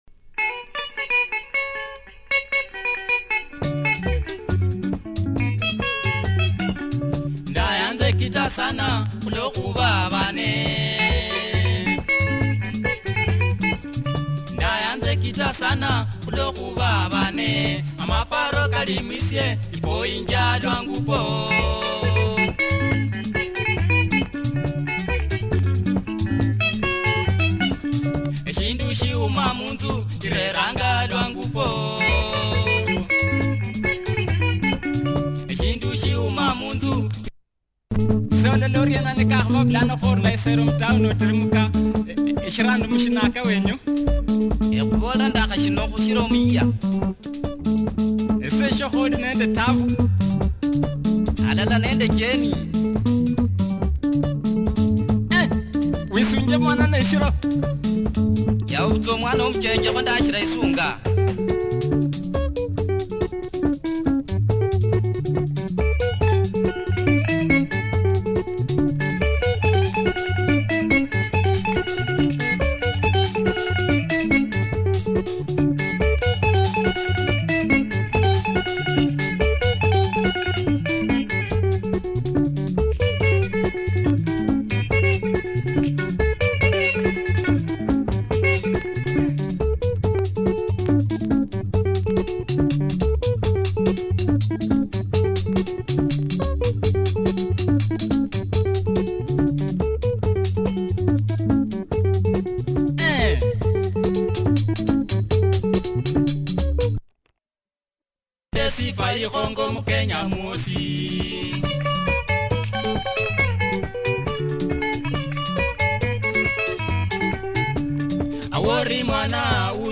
Very cool Luhya Benga, good drive and nice vocals